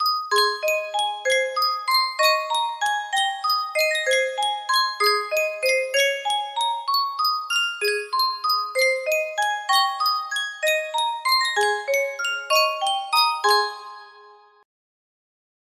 Sankyo Music Box - Auld Lang Syne F
Full range 60